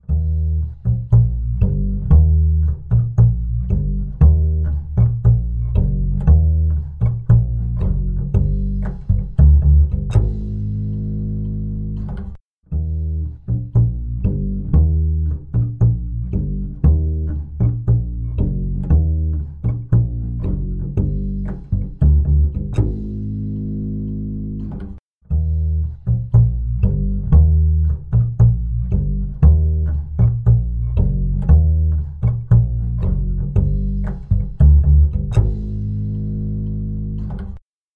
EQ65 | Bass | Preset: Clean the Bass
EQ65-Clean-the-Bass.mp3